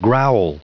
Prononciation du mot growl en anglais (fichier audio)
Prononciation du mot : growl